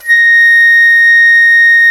FluteClean_A#4.wav